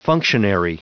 Prononciation du mot functionary en anglais (fichier audio)
Prononciation du mot : functionary